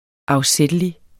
Udtale [ ɑwˈsεdəli ]